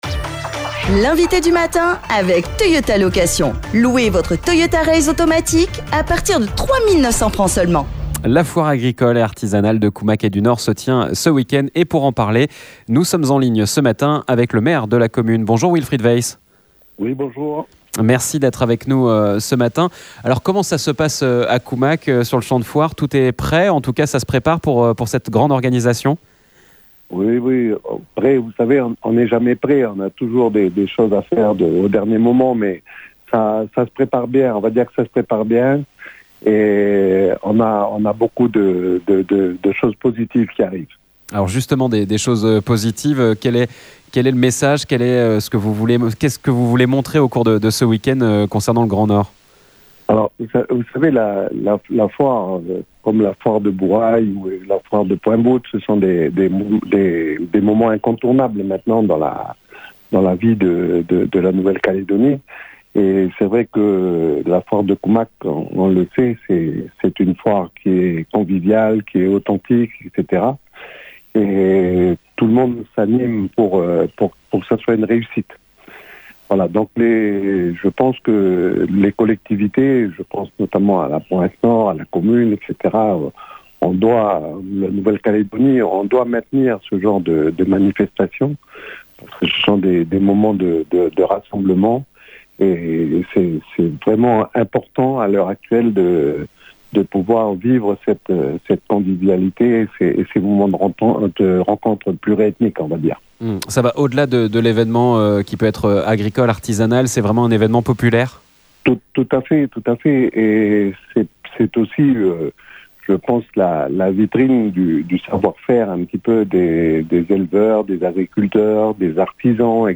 Les visiteurs découvriront plus de 300 exposants qui seront sur le site avec notamment au programme des jeux équestres, un rodéo nocturne. Pour parler de cette foire, et de son rayonnement, c’est le maire de Koumac, Wilfrid Weiss qui est notre invité du matin.